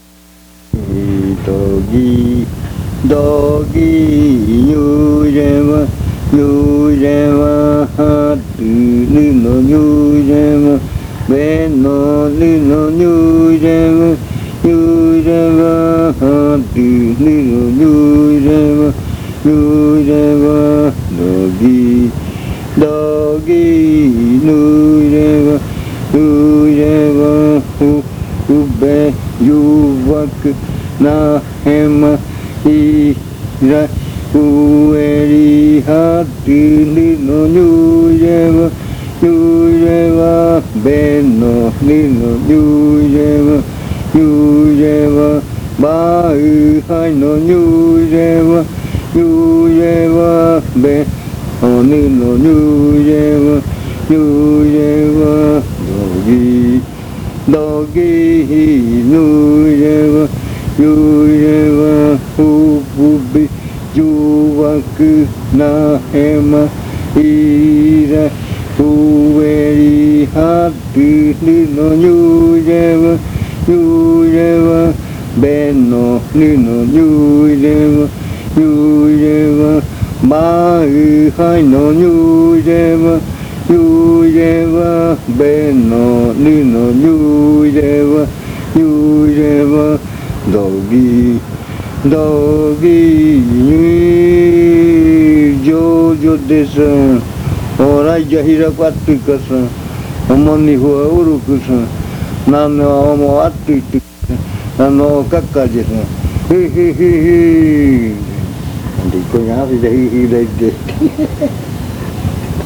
Adivinanza.
01:00 AM. Riddle chant. It says that it is getting into the surroundings.